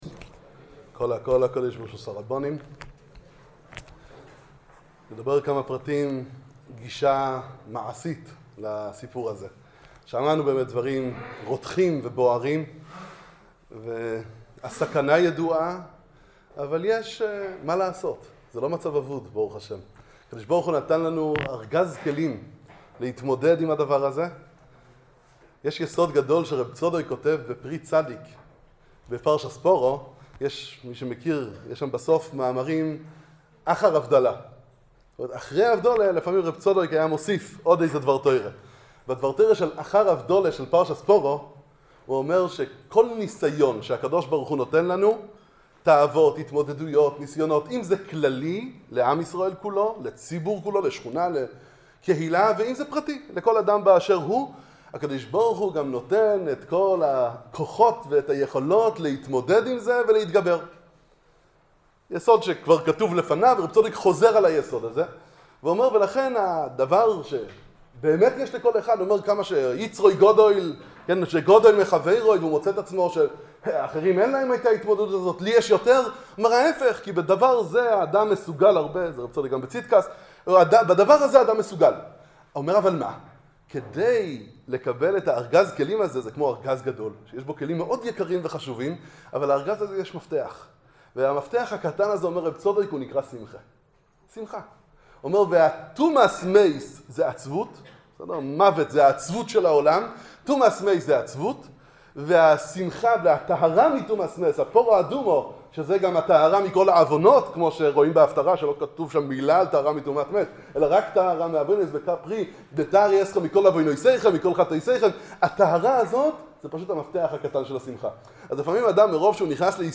דברי חיזוק והתעוררות